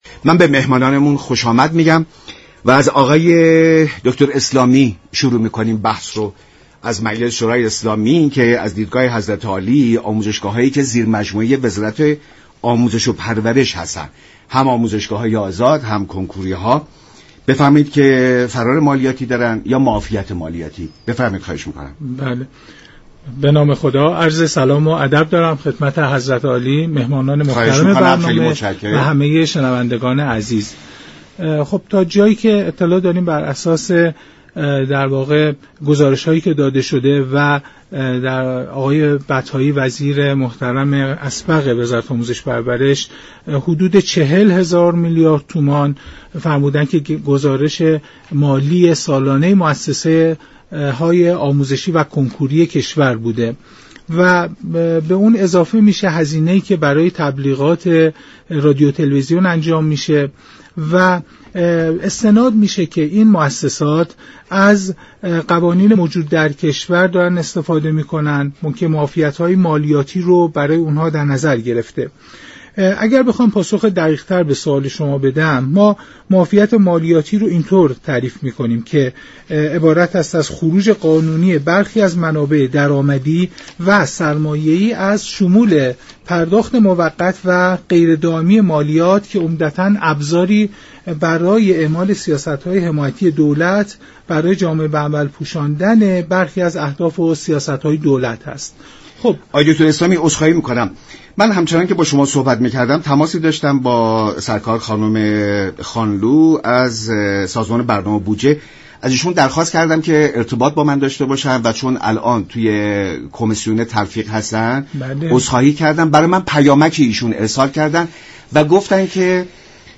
برنامه ایران امروز شنبه تا سه شنبه هر هفته ساعت 12:40 از رادیو ایران پخش می شود.